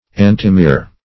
Search Result for " antimere" : The Collaborative International Dictionary of English v.0.48: Antimere \An"ti*mere\, n. [. anti- + -mere.]